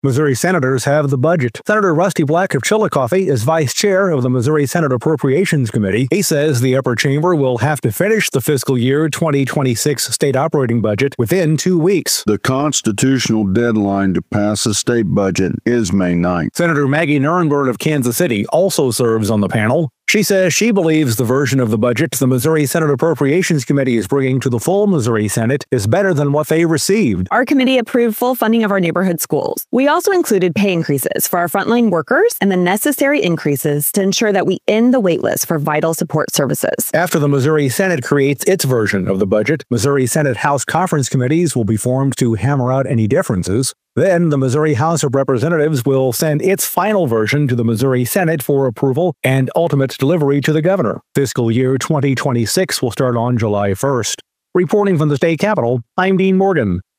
The Missouri Senate is set to take up the Fiscal Year 2026 state operating budget this week, with the deadline to deliver the finalized budget to the executive branch by 6 p.m. on Friday, May 9. Senate Reporter